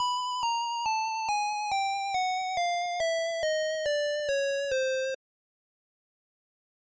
I found a square wave sf2 then I saw waved it